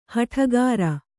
♪ haṭhagāra